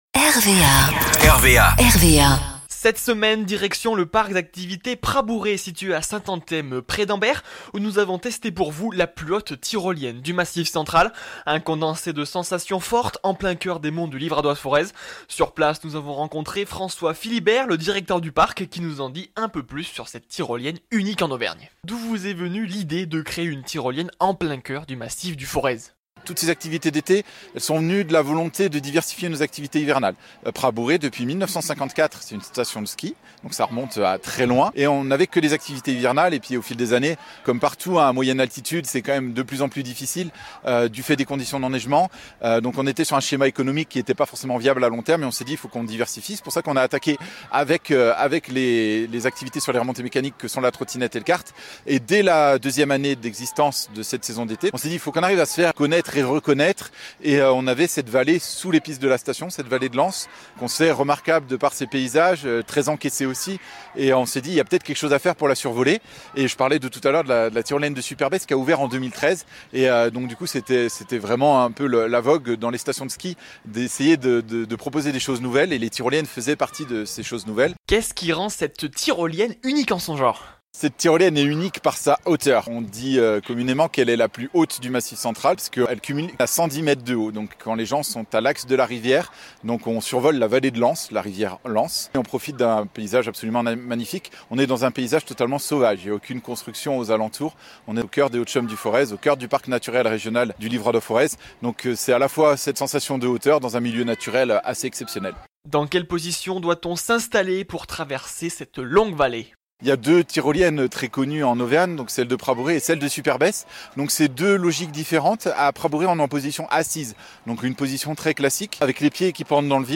Direction le parc d’activités Prabouré situé à Saint-Anthème près d’Ambert où nous avons testé pour vous la plus haute tyrolienne du Massif Central. Un condensé de sensations fortes en plein cœur des monts du Livradois Forez.